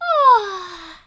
peach_yawning.ogg